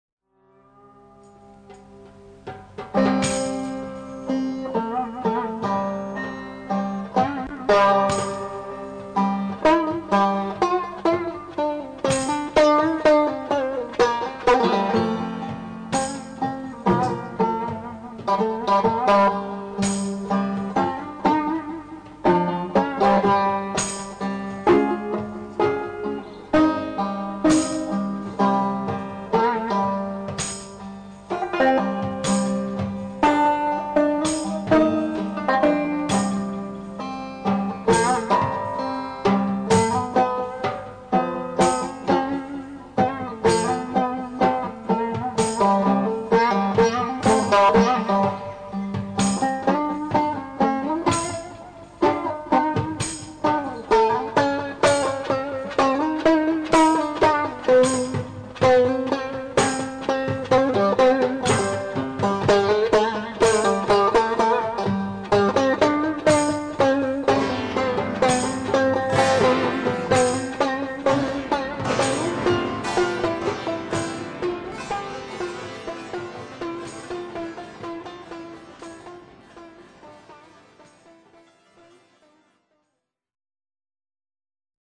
This horrible mess is an exessively edited jam-session.